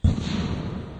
bomb.wav